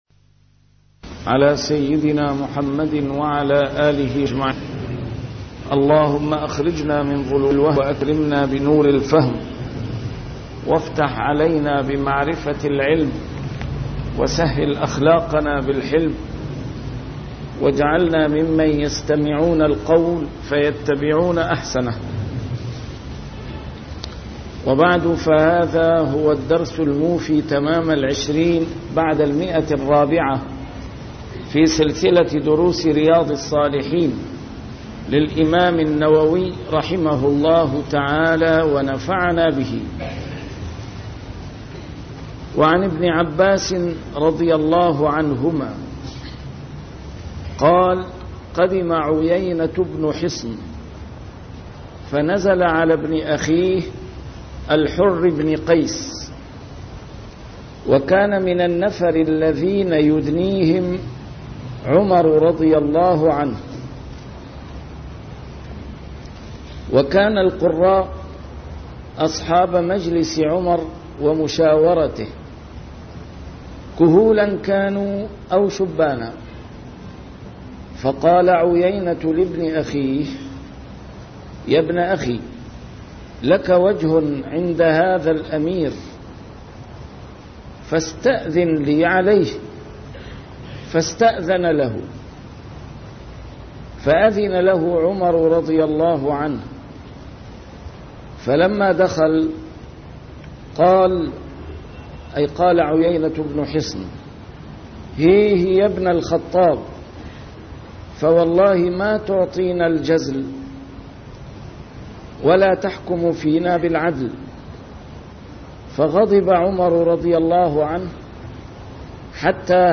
شرح كتاب رياض الصالحين - A MARTYR SCHOLAR: IMAM MUHAMMAD SAEED RAMADAN AL-BOUTI - الدروس العلمية - علوم الحديث الشريف - 420- شرح رياض الصالحين: توقير العلماء